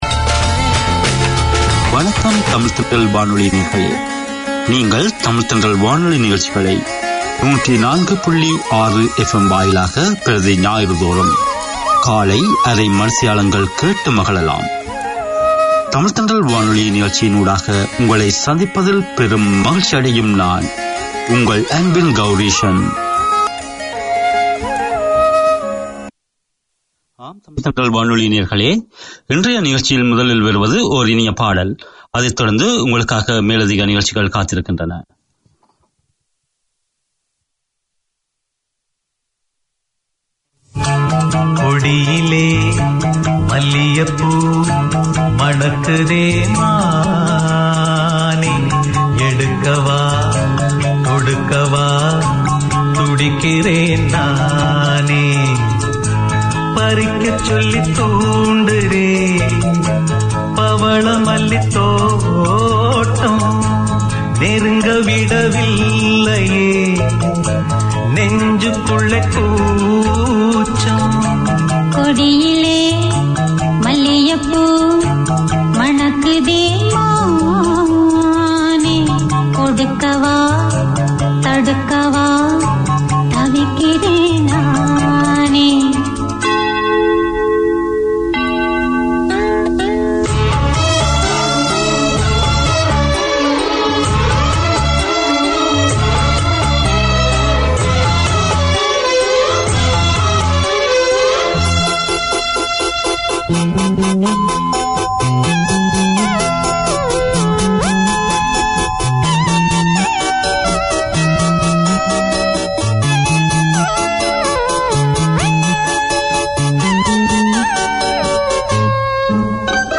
Sri Lankan and Indian news, interviews, Tamil community bulletins, political reviews from Sri Lanka - a wealth of cultural content with drama, stories, poems and music.